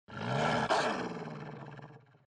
Bruit de moteur V8 des empafés :D
Moi aussi ça m'a fait sursauté la première fois (au fait c'est pas un son de moteur c'est le grognement d'un bulldog).